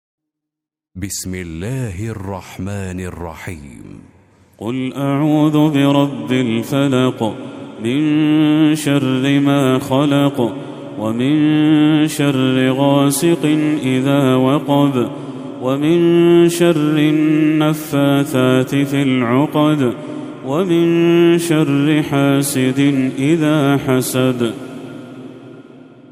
سورة الفلق Surat Al-Falaq > المصحف المرتل